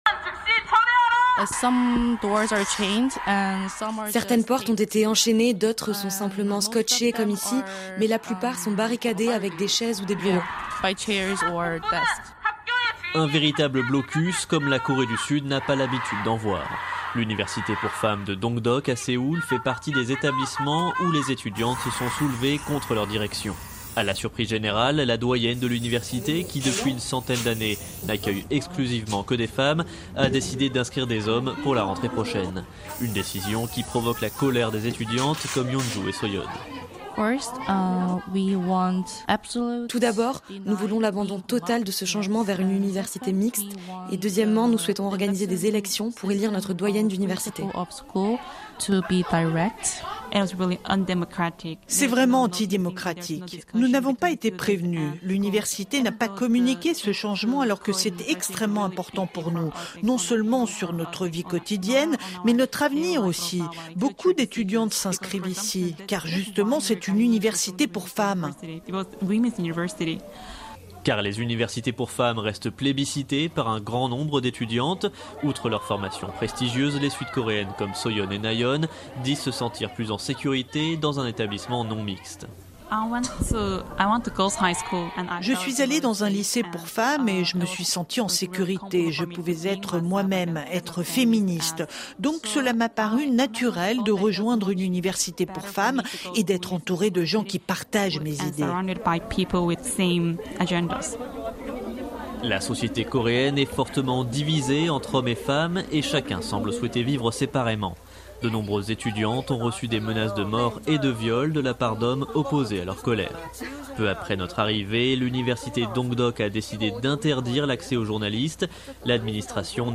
Chaque jour, l’illustration vivante et concrète d’un sujet d’actualité. Ambiance, documents, témoignages, récits en situation : les reporters de RFI présents sur le terrain décrivent le monde avec leur micro.